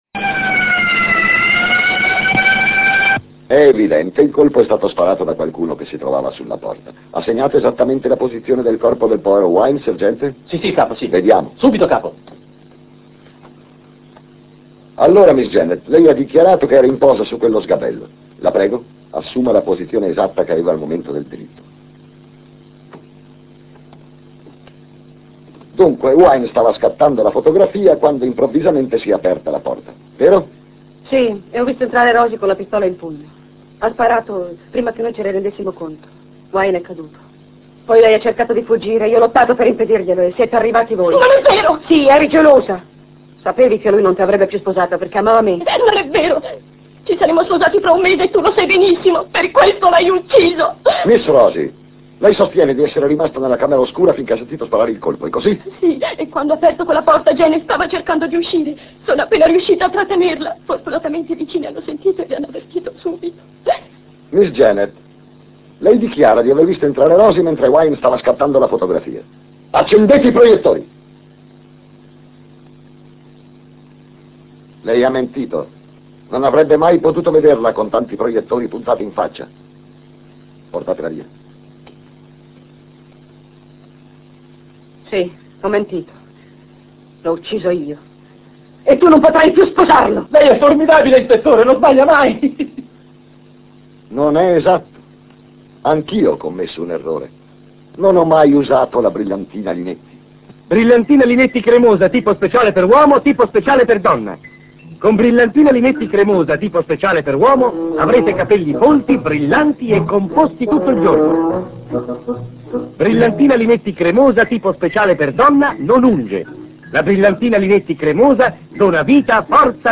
voce di Cesare Polacco da "Carosello", in una delle sue celebri scenette dell'ispettore Rock che pubblicizza la brillantina Linetti.